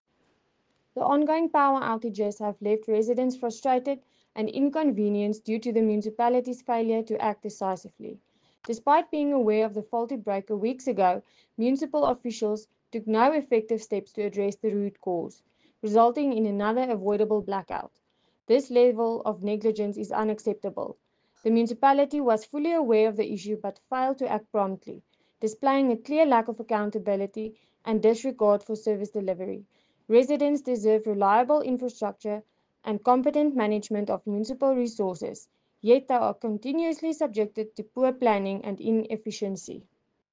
Afrikaans soundbites by Cllr Marié la Cock and